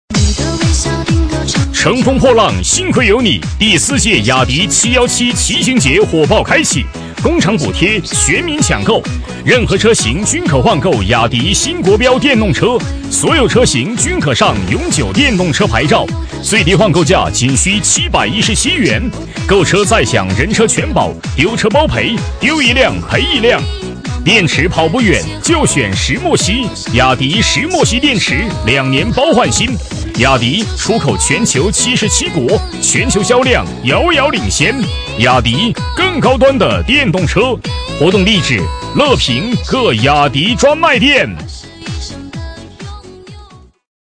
【男8号促销】乘风破浪雅迪717
【男8号促销】乘风破浪雅迪717.mp3